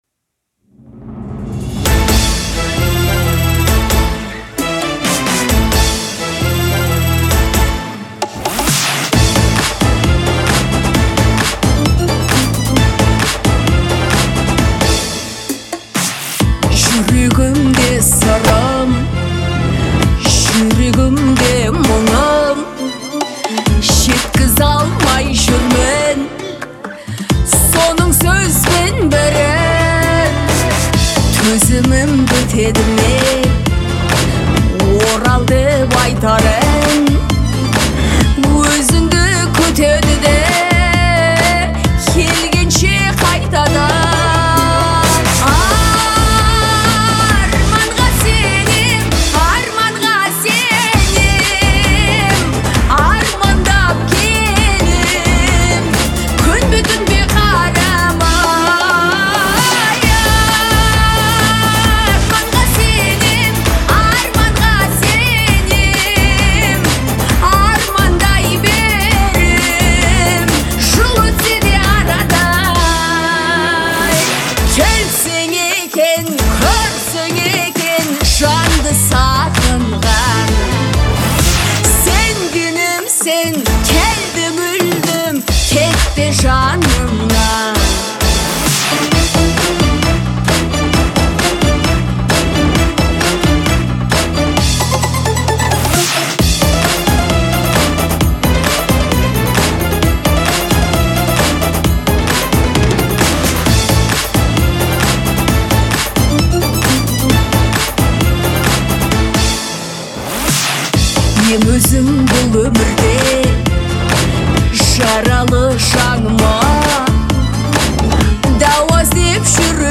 мощный вокал